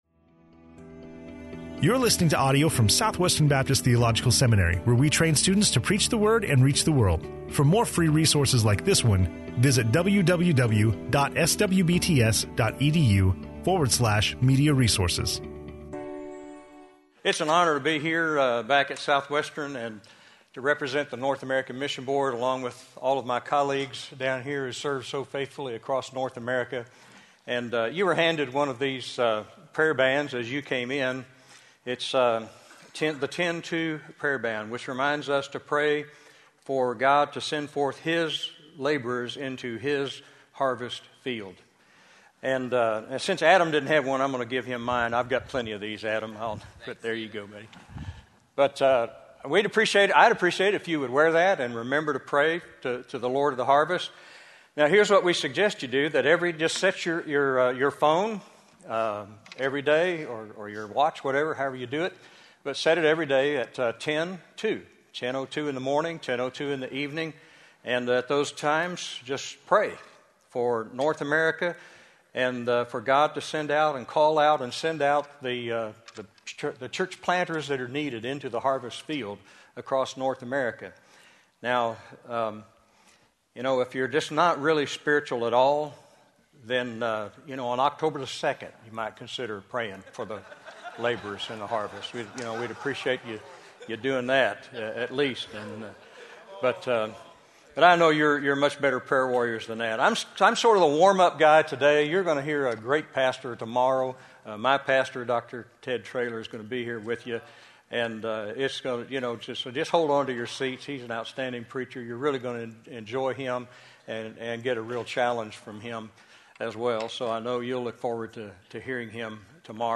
in SWBTS Chapel on Tuesday March 25, 2014
SWBTS Chapel Sermons